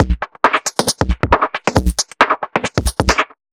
Index of /musicradar/uk-garage-samples/136bpm Lines n Loops/Beats
GA_BeatFilterB136-01.wav